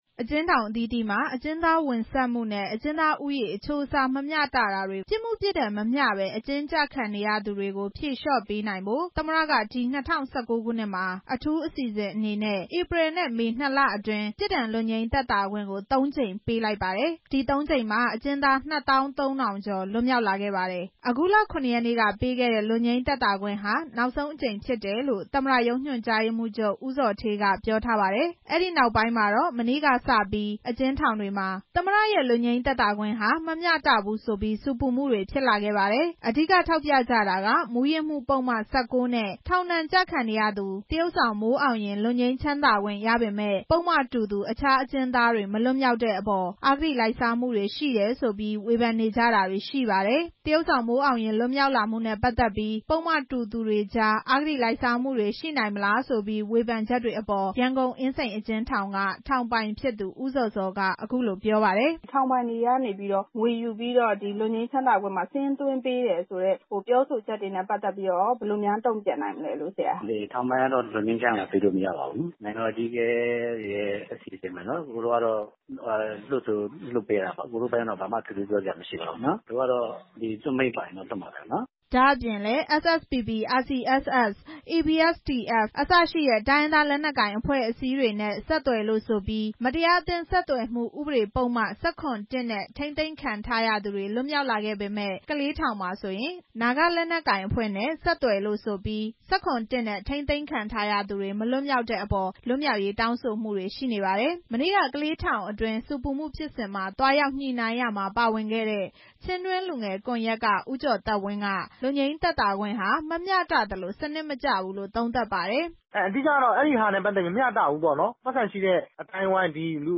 ၂ဝ၁၉ ခုနှစ်အတွင်း ပေးခဲ့ပြီးဖြစ်တဲ့ သမ္မတရဲ့ ပြစ်ဒဏ်လွတ်ငြိမ်းသက်သာခွင့်ဟာ မျှတမှုရှိပါသလားဆိုတဲ့အပေါ် အကျဉ်းသားဟောင်းတွေ၊ ထောင်ပိုင်တွေ၊ ထောင်မှူးဟောင်းတွေရဲ့ ပြောဆိုချက်တွေကို